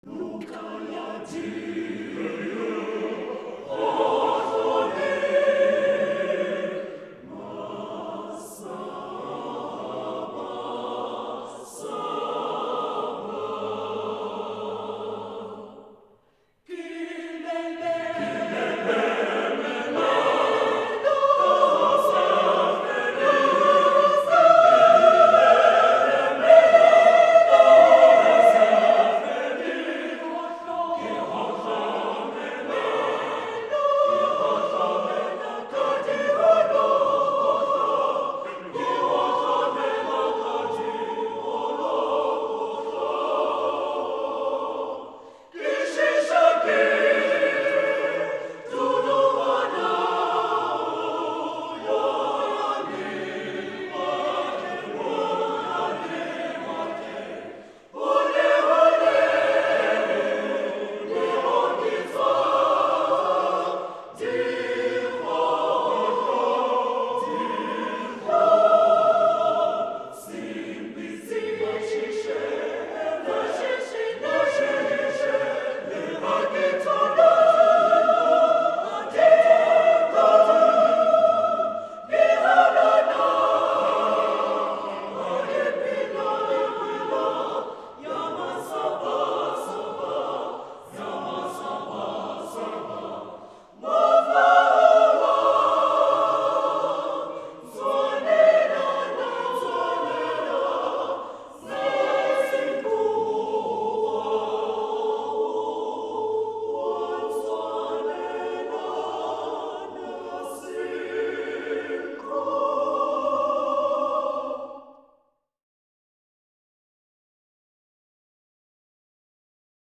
African chorus
flowing 9/8 meter
Type: Live Recording Performers
choir